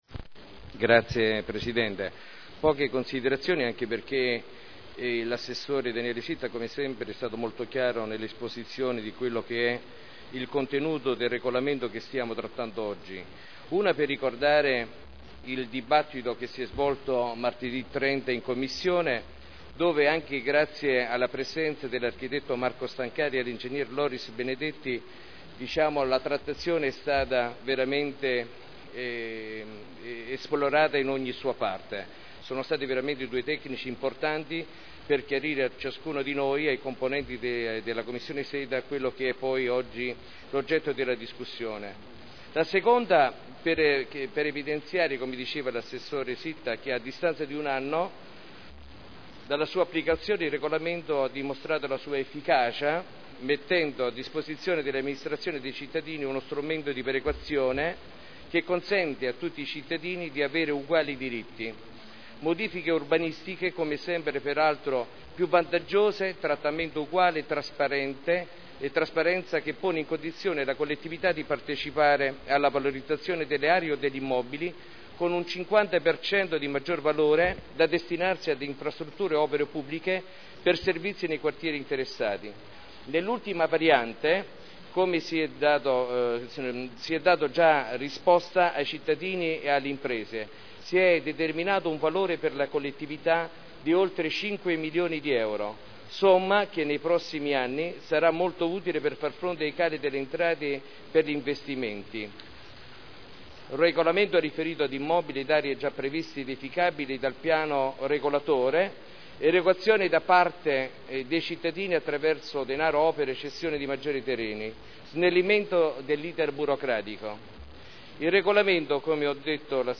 Daniele Sitta — Sito Audio Consiglio Comunale
Seduta del 6/12/2010. Dibattito su delibera: Art. 14.1 del testo coordinato delle norme di PSC (Piano Strutturale Comunale) POC (Piano Operativo Comunale) RUE (Regolamento Urbanistico Edilizio) – Modifiche al regolamento attuativo – Approvazione (Commissione consiliare del 30 novembre 2010)